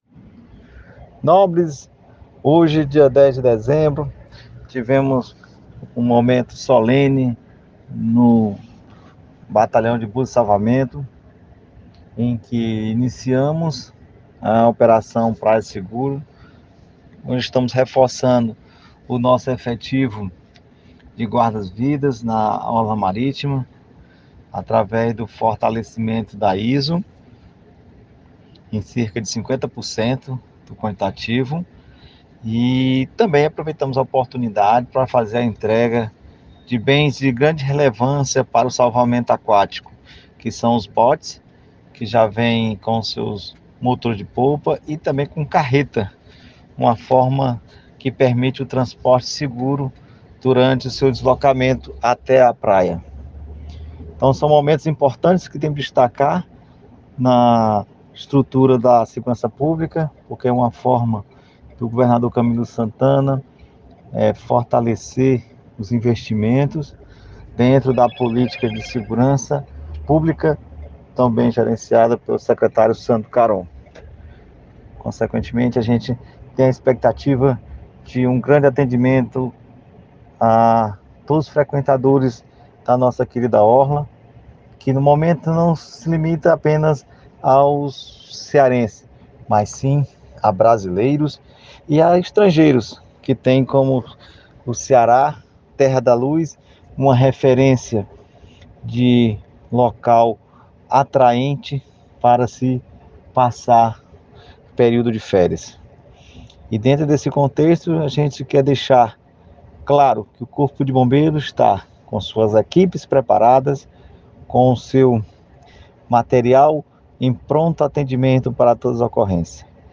Palavras do Comandante Ronaldo Roque
Áudio do Coronel Comandante-Geral do Corpo de Bombeiros Militar do Estado do Ceará (CBMCE).